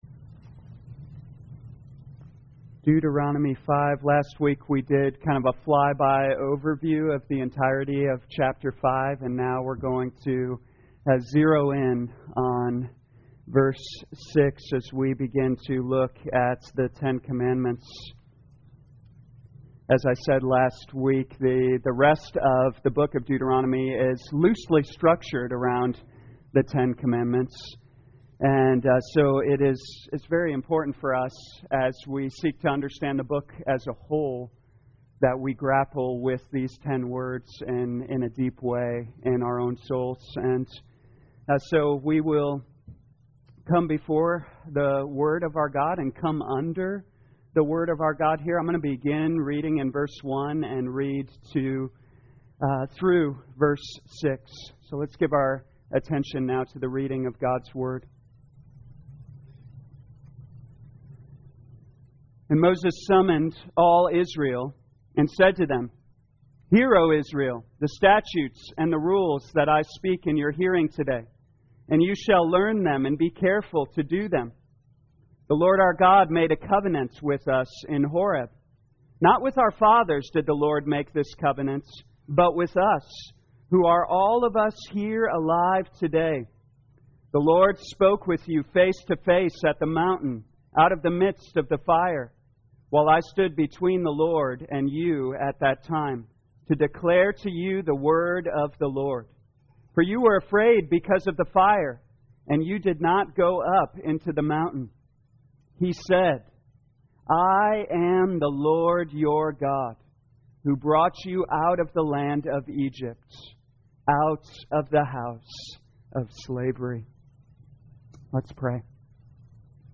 2021 Deuteronomy The Law Evening Service Download